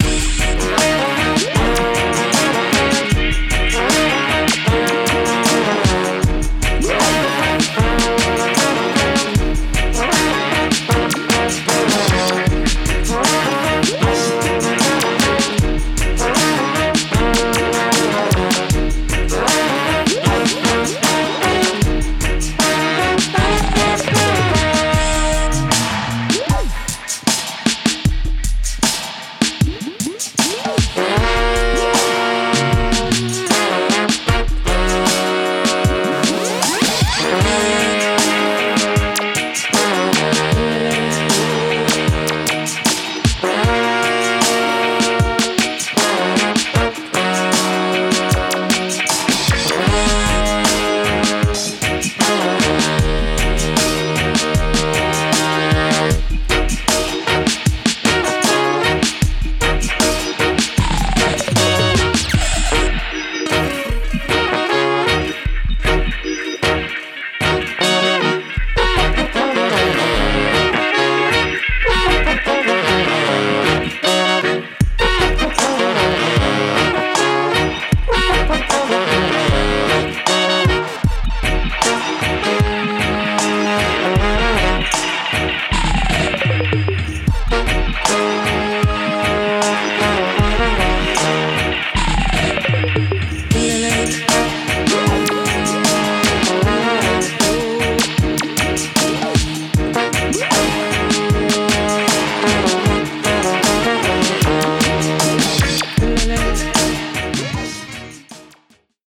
Genre:Reggae
43 Dry Loops
24 One shots
Tempo Range: 77bpm
Key: Ebm